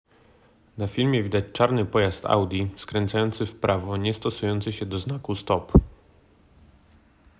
Opis nagrania: audiodeskrypcja Audi